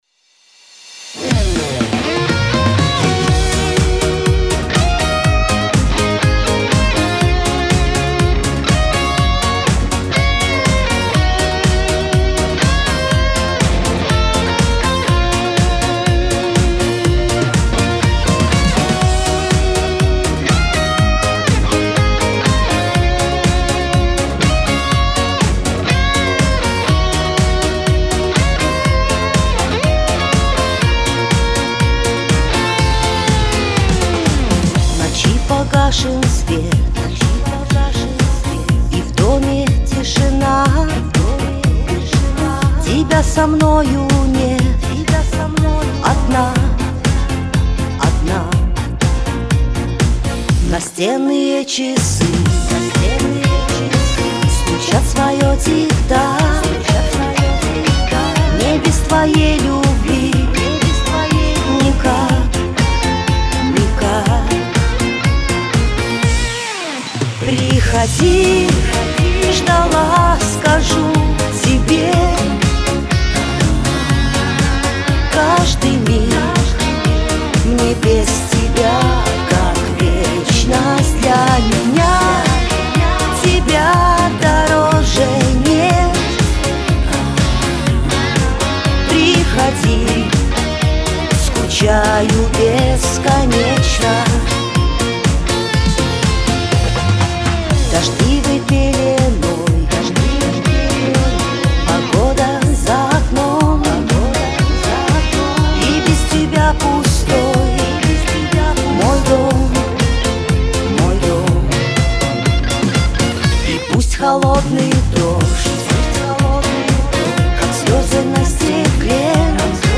Прекрасная подача песни в мужском исполнении!